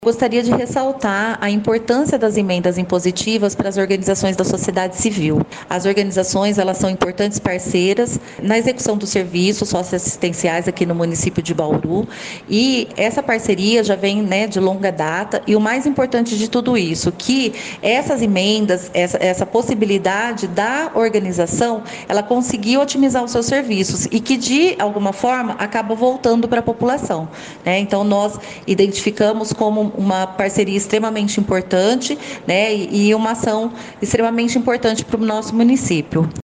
Secretária do Bem Estar Social, Ana Cristina Sales destaca a importância desses recursos para as organizações da sociedade civil.